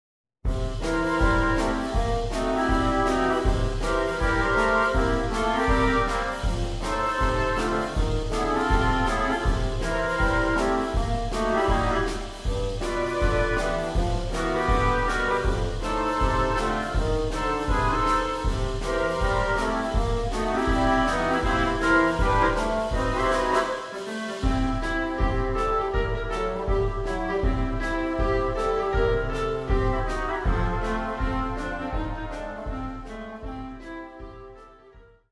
simple boogie based tunes